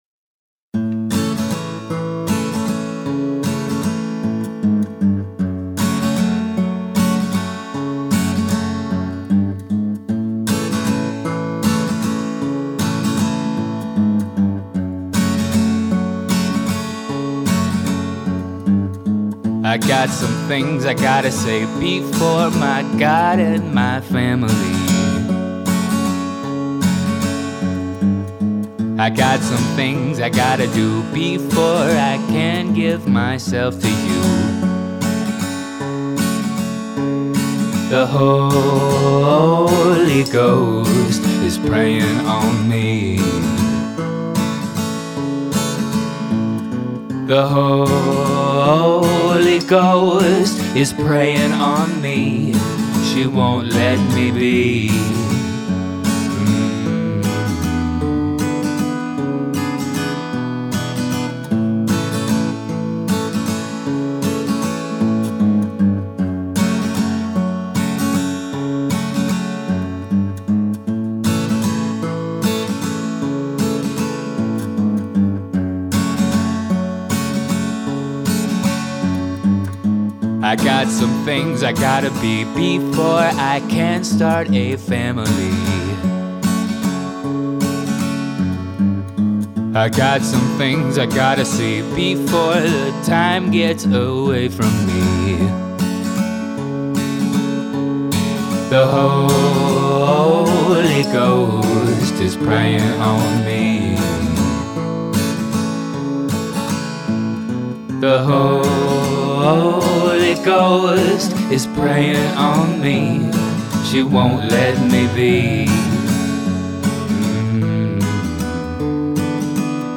This is just an acoustic guitar and vocals so I can't really hide behind any mixing tricks.
It was sung into an SM7b, which I rarely ever use, and I can't even remember the acoustic mic, but it wasn't recorded in stereo.
There's a natural saturation on it (I guess from how haphazardly I set up the vocal chain since it was just a demo, but I'm good with that).
The lead vocal seems a bit loud overall compared to the guitar. The backing vocal also seems too loud in relation to the lead vocal.
The double on the chorus is spot on and sounds great.
My first instinct was that the acoustic guitar sounds a little thin, especially seeing as it's the only accompanying instrument.
They were just too thick and wide and pushed the main vocals away from your attention.